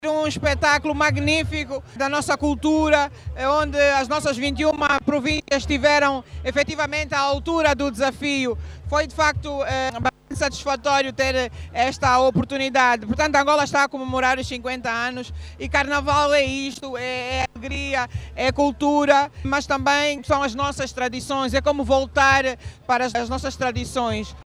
O desfile, de carácter não competitivo serviu para enaltecer a cultural das distintas regiões do País, segundo a Vice-presidente do MPLA, Mara Quiosa.